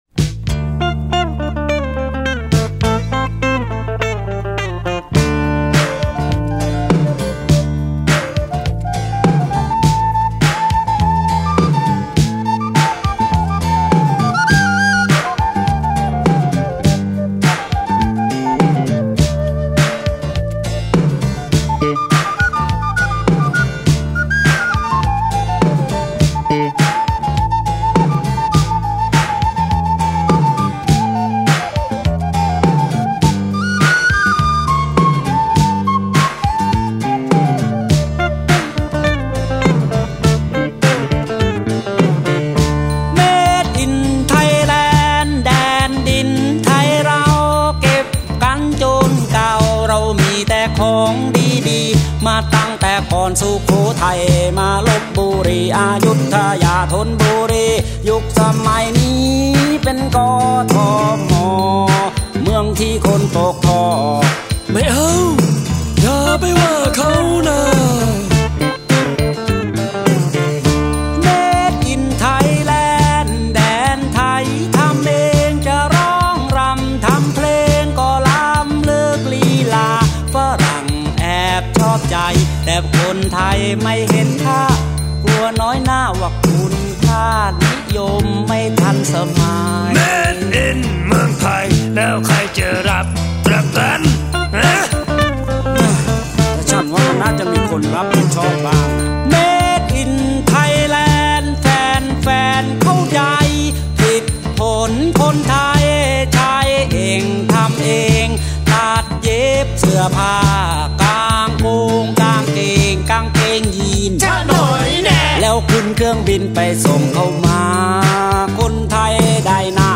节奏很好，但声音让人想起泰拳。